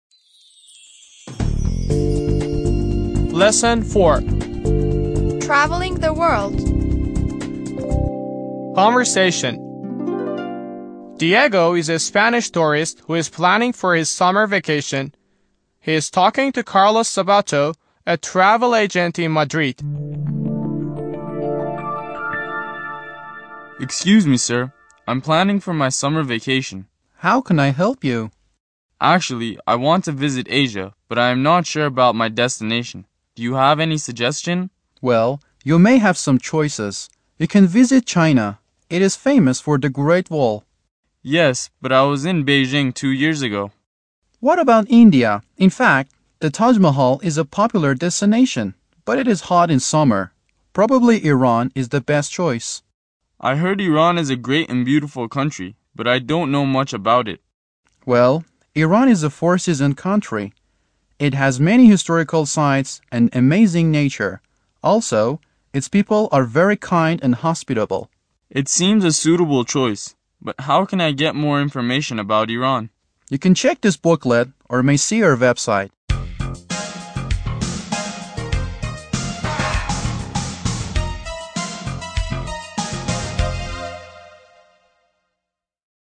10-L4-Conversation